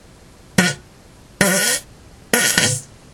toilet fart11